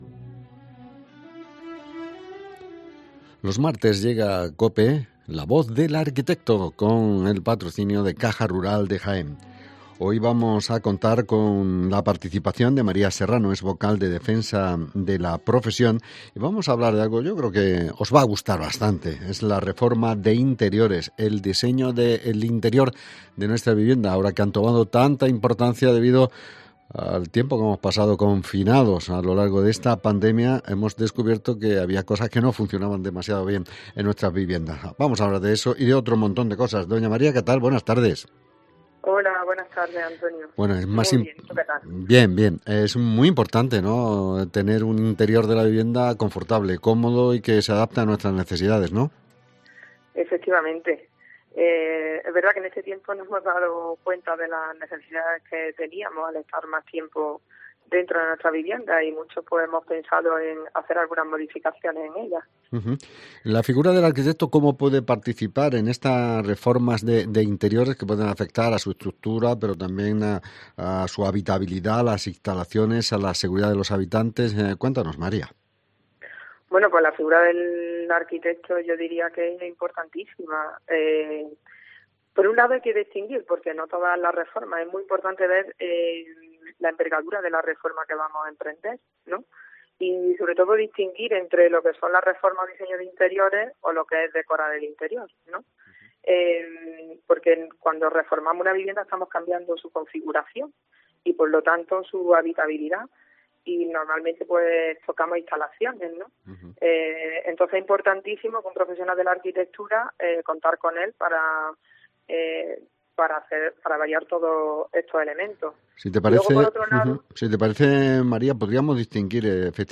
Espacio semanal denominado “La Voz del Arquitecto”, bajo el Convenio de Colaboración con COPE cuyo emisión tiene lugar los martes en la citada cadena, con el patrocinio de Caja Rural de Jaén.